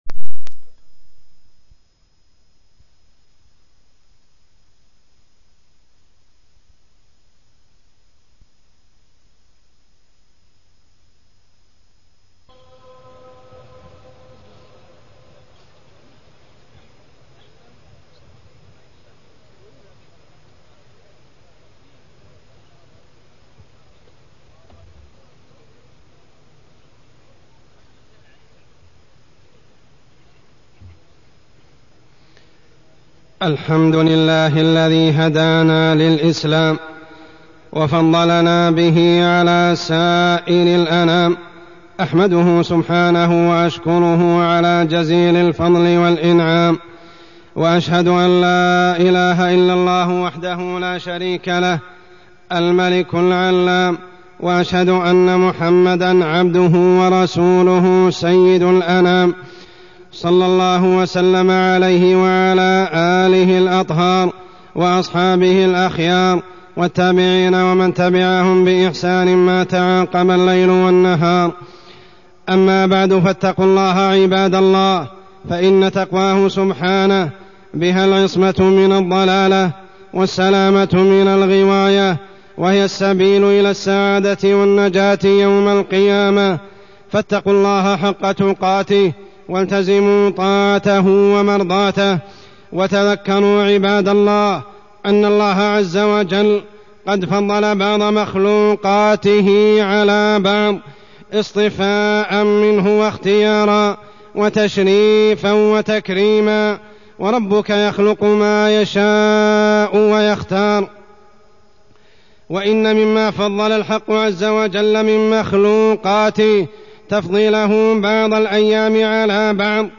تاريخ النشر ١٧ صفر ١٤٢٢ هـ المكان: المسجد الحرام الشيخ: عمر السبيل عمر السبيل فضل يوم الجمعة